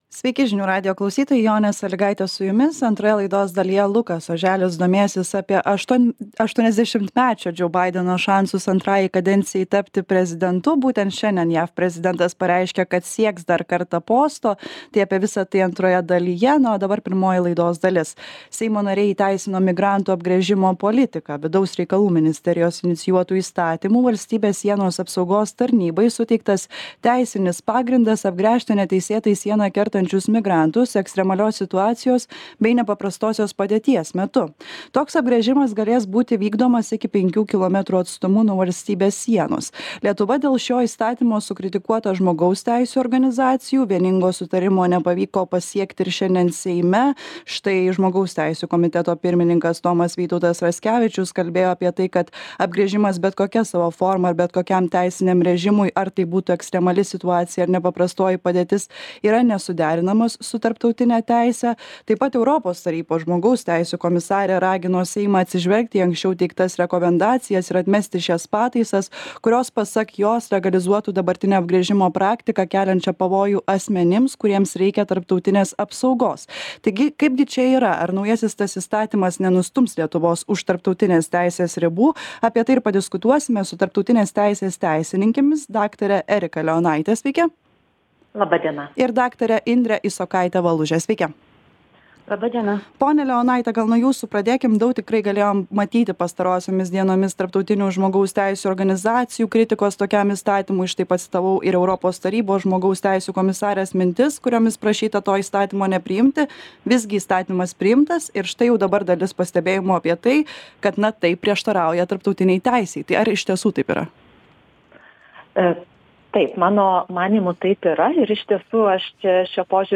Aktualusis interviu Atsisiųsti Migrantų apgręžimo įstatymas